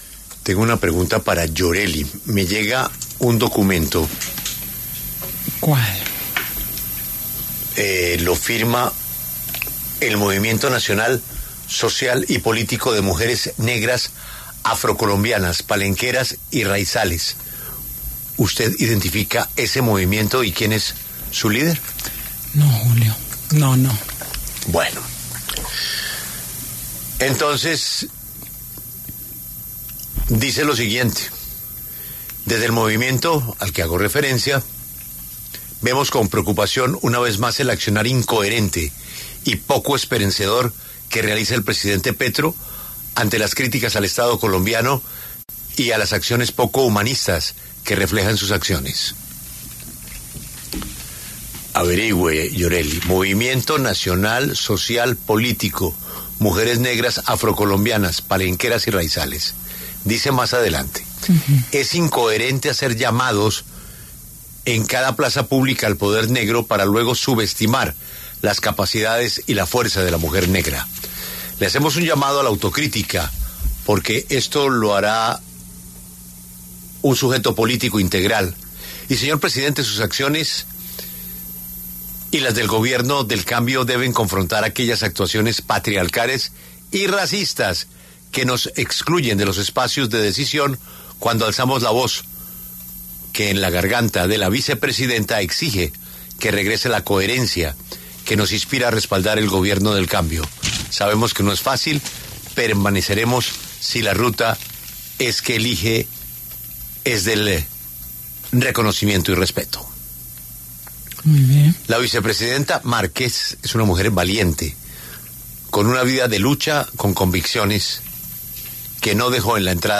El director de La W, Julio Sánchez Cristo, leyó la carta del Movimiento Nacional Social y Político de Mujeres Negras Afrocolombianas, Palenqueras y Raizales (MesaMujeresAfro) en el que califican como “incoherentes” y “preocupantes” las acciones del presidente Gustavo Petro con las comunidades afro de Colombia.